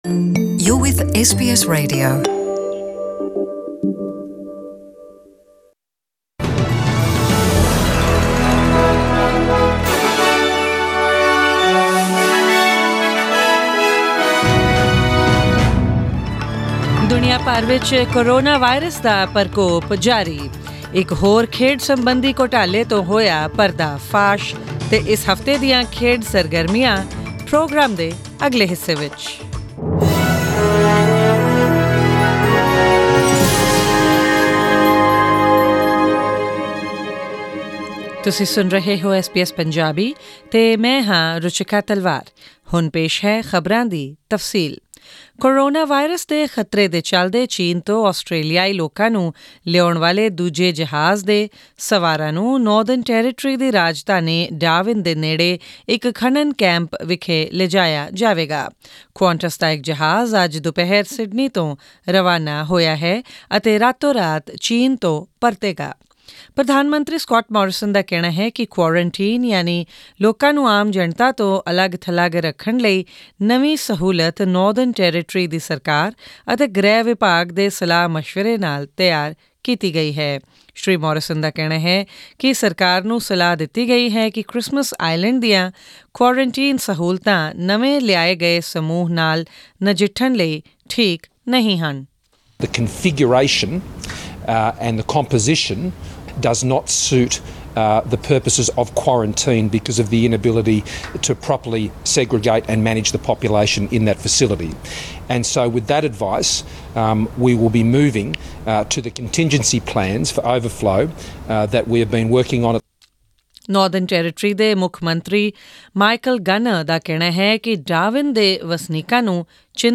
** This week's sports wrap, up ahead in tonight's bulletin ...